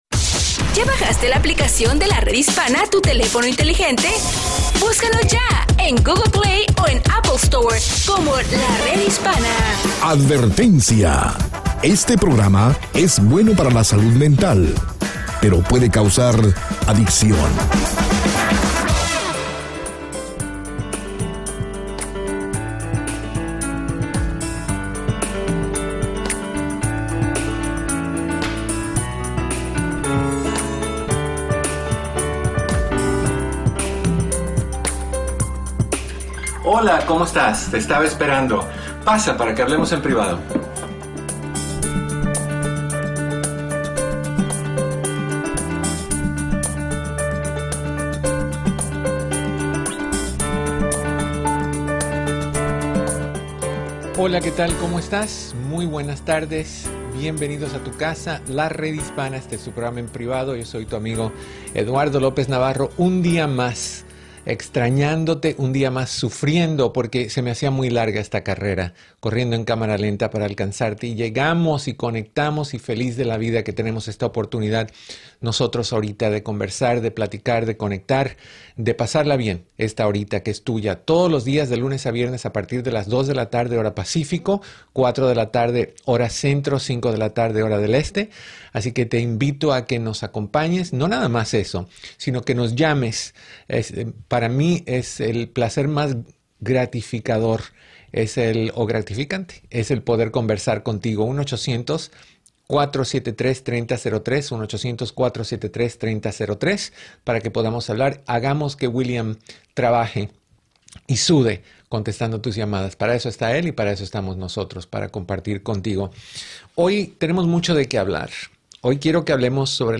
Escucha el programa de radio EN PRIVADO, de Lunes a Viernes a las 2 P.M. hora del Pacífico, 4 P.M. hora Central y 5 P.M. hora del Este por La Red Hispana y todas sus afiliadas.